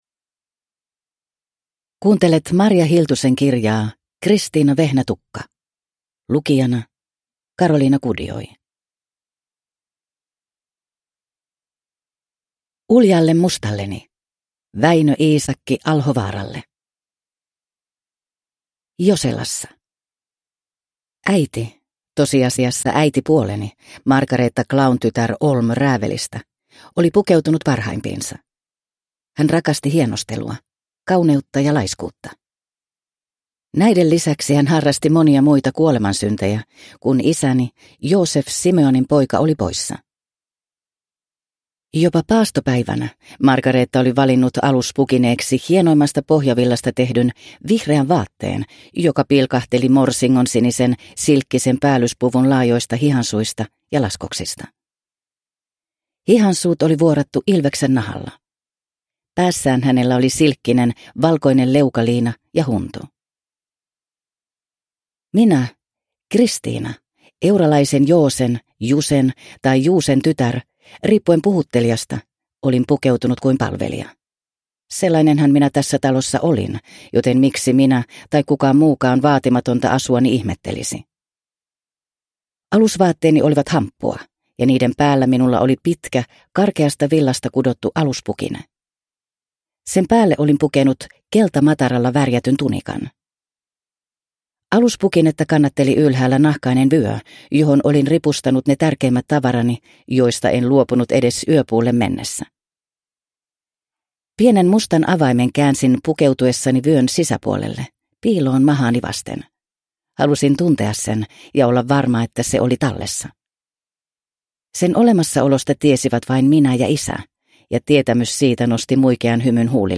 Kristiina Vehnätukka – Ljudbok – Laddas ner